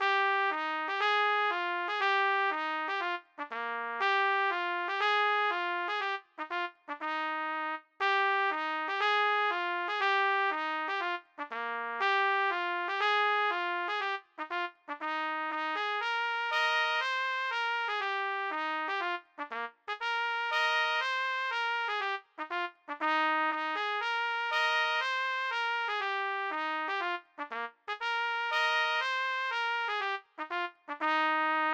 A=Melody-for more experienced players